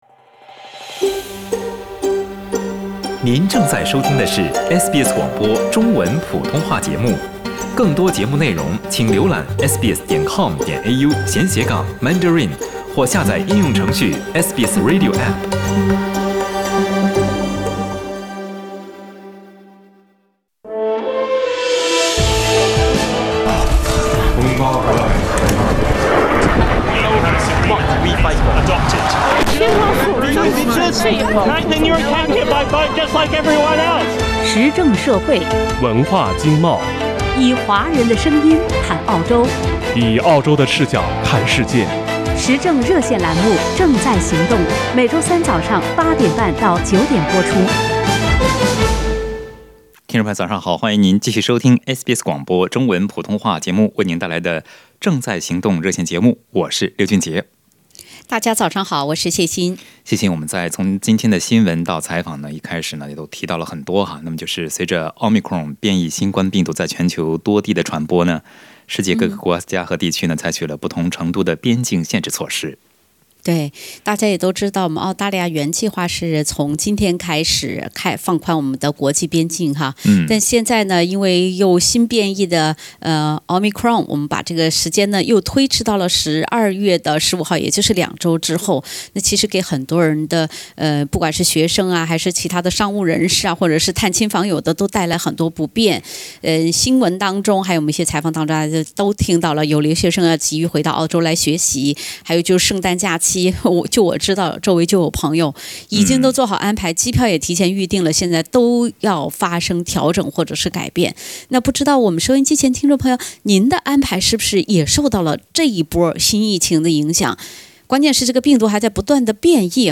在本期《正在行动》节目中，多位听友表示，他们被迫调整了假期行程安排。
请您点击收听本期《正在行动》热线节目的完整内容。